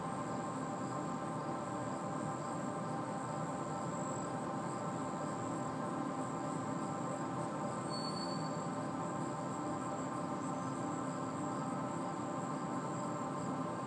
I found a communications cabinent making an ambient drone noiise
Last week I was walking around outside and passed an AT&T communications cabinet and noticed ever so faintly that it was making this beautifully harmonic ambient noise. I am guess it is from the A/C inside passing through some of the vents. I tried recording a snippet with my iPhone.
Tags: ambient, field recording, found object.
I like the layer of insect sounds with it too.
Ambient_Telecom_Cabinet.m4a